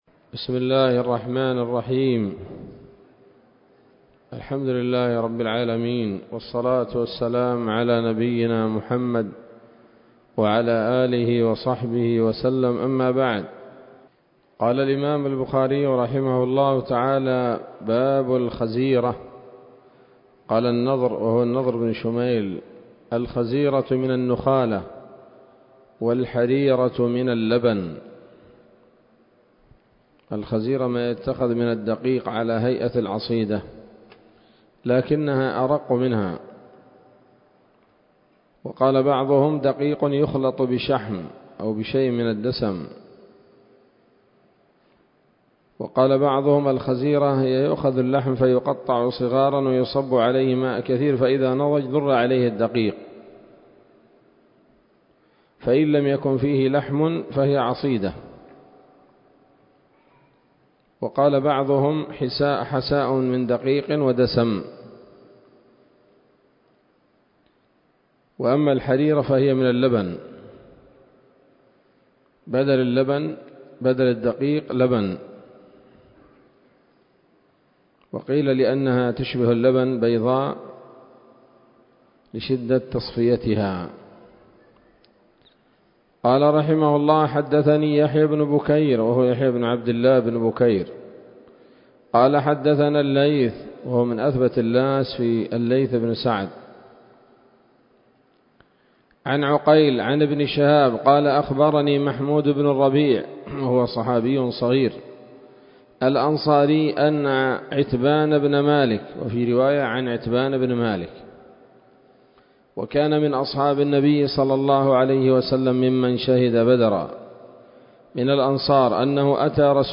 الدرس الثاني عشر من كتاب الأطعمة من صحيح الإمام البخاري